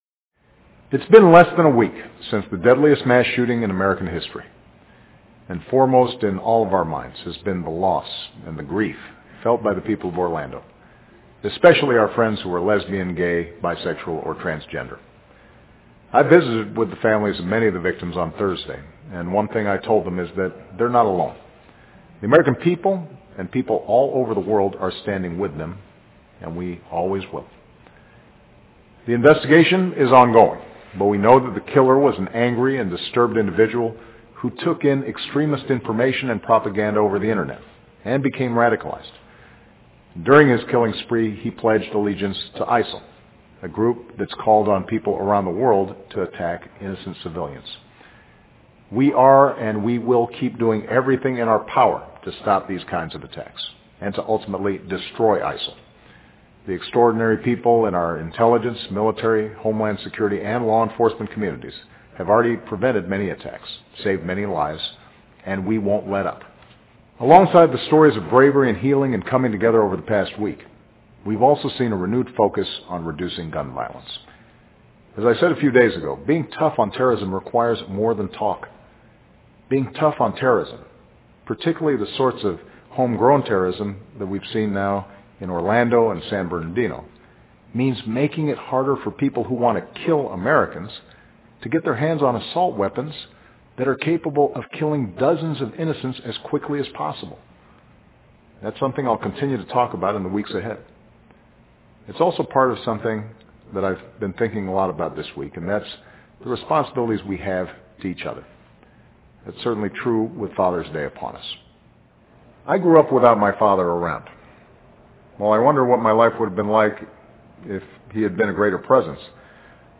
奥巴马每周电视讲话：奥巴马父亲节讲话：与奥兰多同在 听力文件下载—在线英语听力室